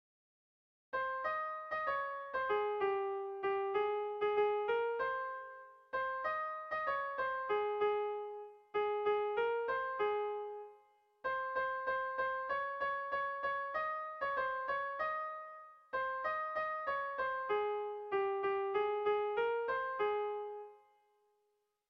Air de bertsos - Voir fiche   Pour savoir plus sur cette section
Irrizkoa
Zortziko txikia (hg) / Lau puntuko txikia (ip)
A1A2BA1